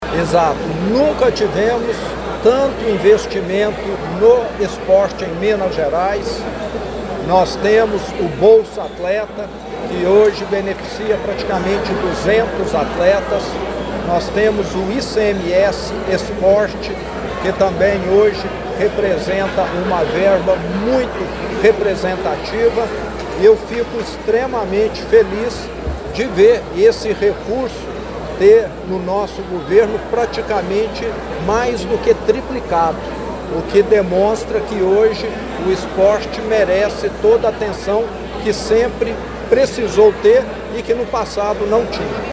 O evento ocorreu na quadra poliesportiva da Escola Estadual Nossa Senhora Auxiliadora, no bairro São Cristóvão, com a presença do governador do Estado de Minas Gerais, Romeu Zema, que ressaltou a importância de Pará de Minas para o desporto estudantil.